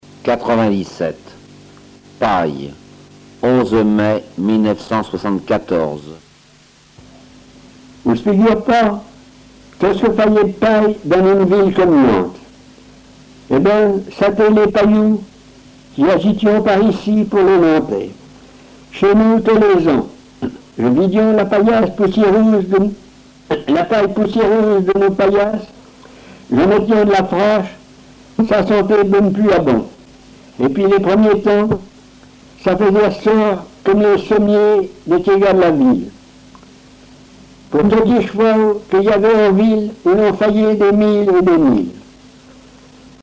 Genre récit
Récits en patois